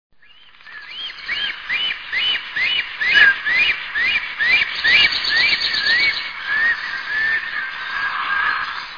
Bocian czarny - Ciconia nigra
głosy